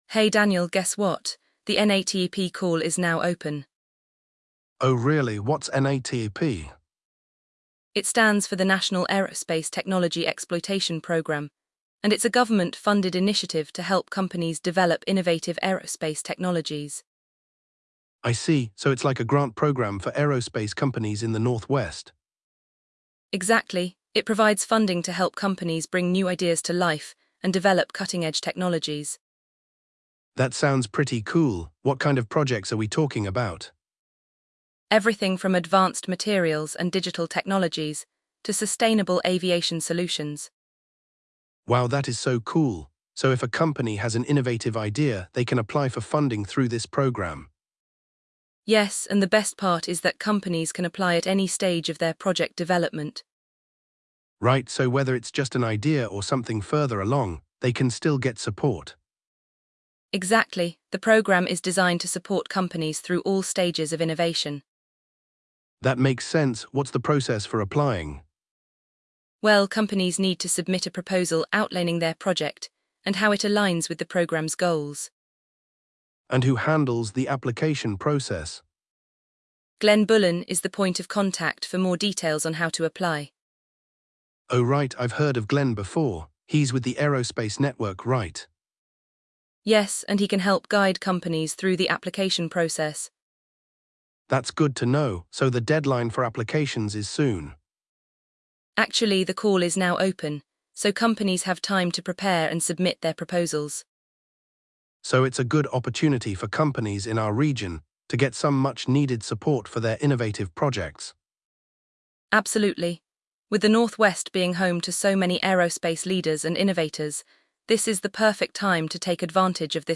The duo discusses what kind of projects qualify, how to apply, and who to contact for more details.